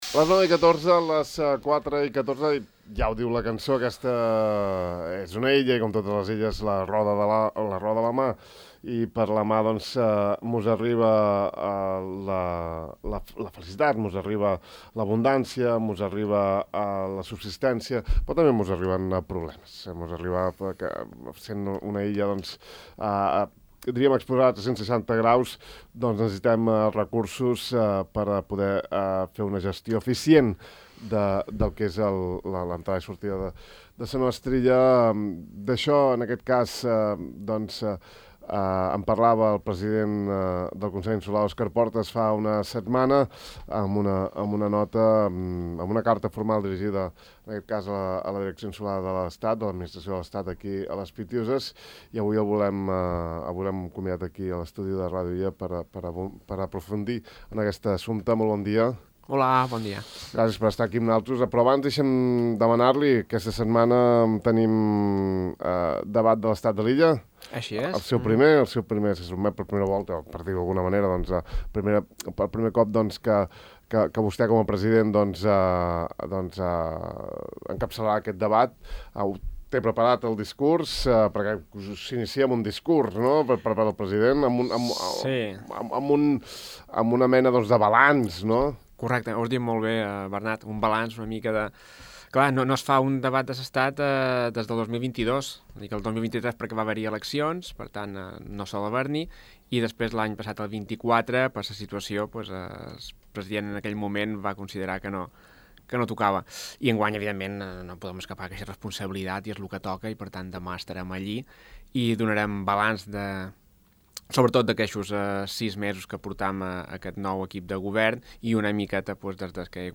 Aquesta setmana hem parlat amb el president del Consell Òscar Portas sobre la seva petició a l’estat de més mitjans i recursos per a una millor i més eficaç vigilància marítima. En l’entrevista, Portas també s’ha referit a la no-visita de la secretària d’estat de migracions a Formentera i una reflexió que el president no li va poder transmetre.